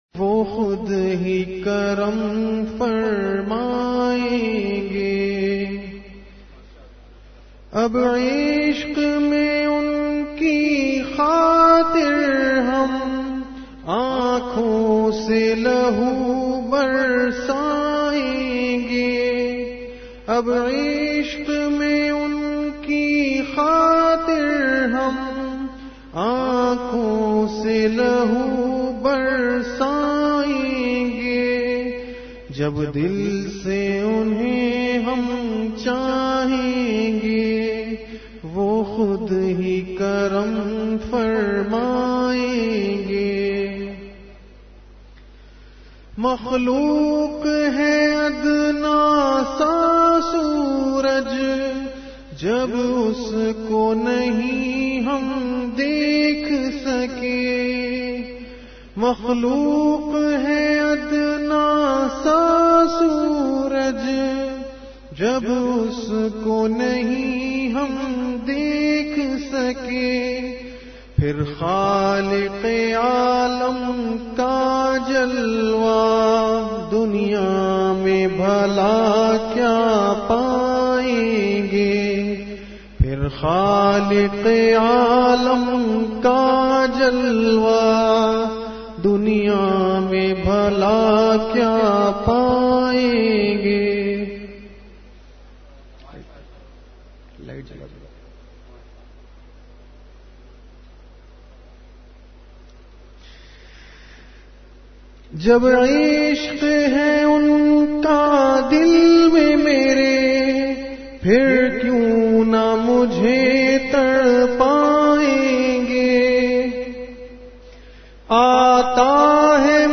اس ضمن میں بیان اور اشعار بھی پیش فرمائے۔
اصلاحی مجلس